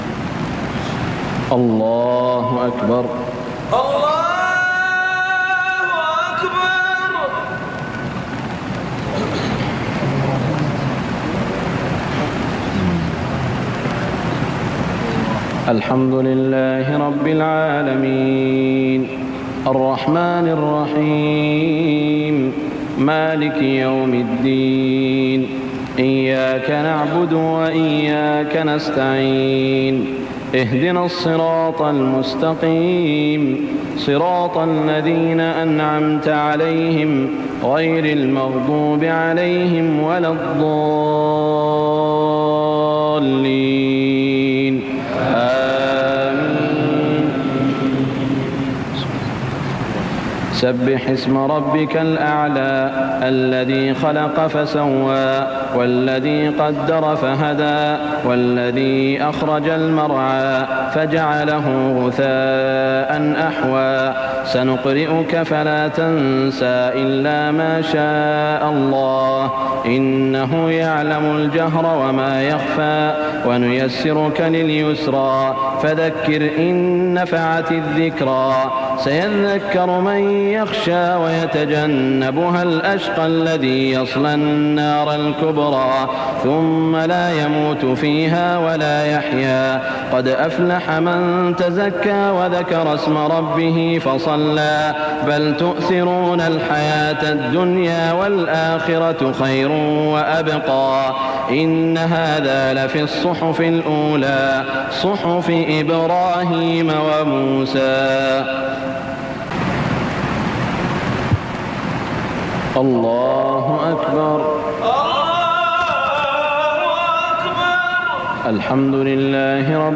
صلاة الجمعة 19 ربيع الأول 1415هـ سورتي الأعلى و الغاشية > 1415 🕋 > الفروض - تلاوات الحرمين